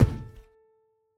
Place.mp3